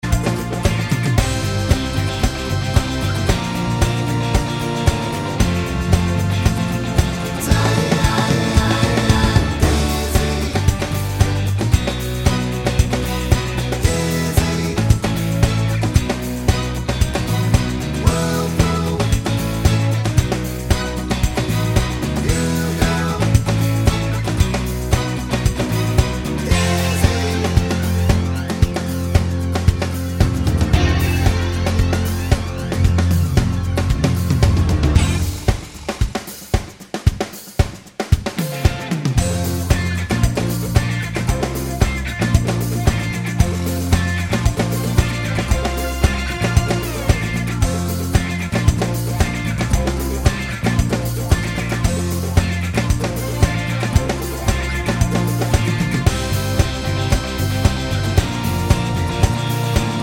No End Ad Libs Pop (1990s) 3:16 Buy £1.50